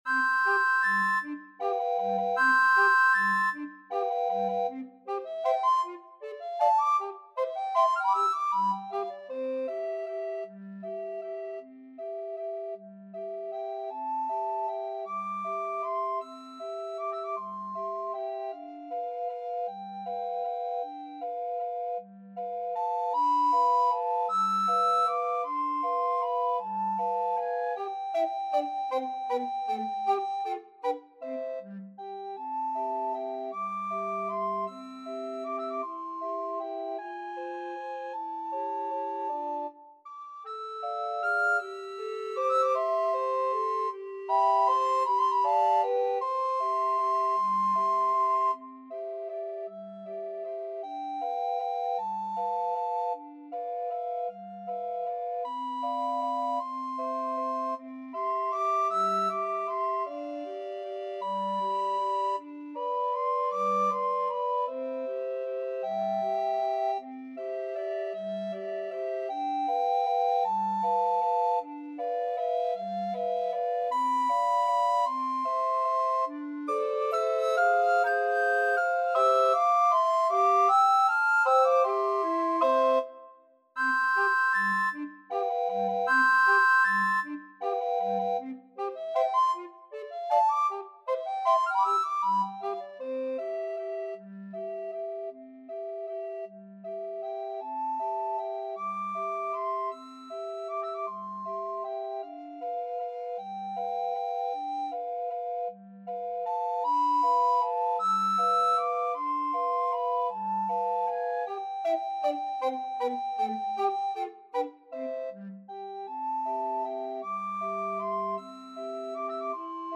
Soprano RecorderAlto RecorderTenor RecorderBass Recorder
6/8 (View more 6/8 Music)
Allegretto . = c.52
Classical (View more Classical Recorder Quartet Music)